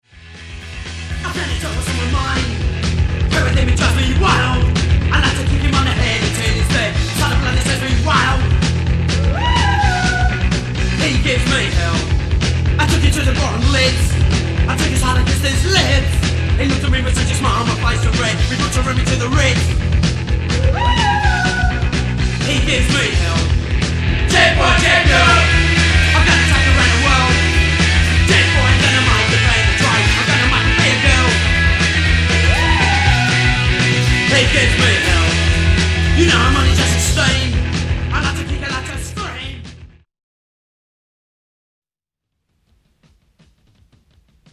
Vocals/Guitar
Drums
Bass